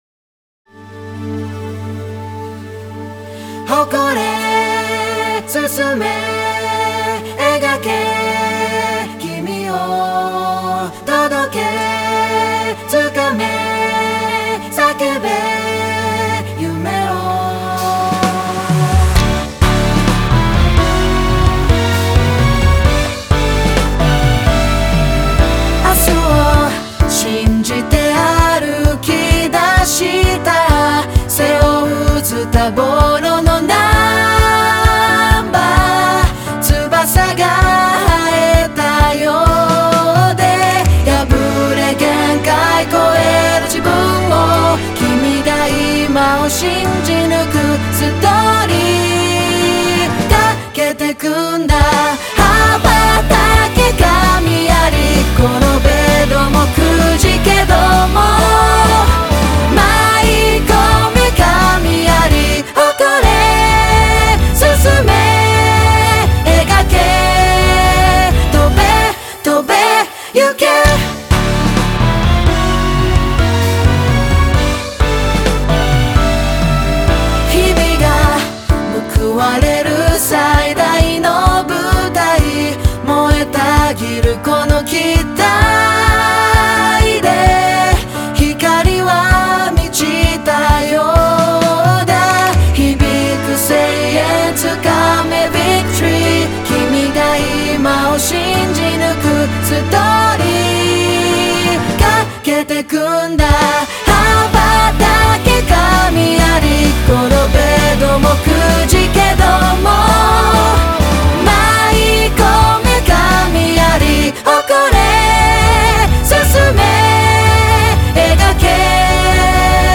選定理由 大会の愛称に合致することに加え、人の声から始まることが印象的で、街中で流れてきても自然と耳に残り、メロディやサビも覚えやすく、ダンスとしても活用しやすいこと、幅広い年齢層の方が聞いても口ずさみやすく、聴いていて飽きないこと、選手一人ひとりの顔が思い浮かぶ点等が評価されました。
希望、闘志を燃やせるサウンドをイメージしています。
一度聴いて歌えるメロディにしていますので、たくさんの方に歌っていただき、皆様の挑戦に寄り添えたら幸いです。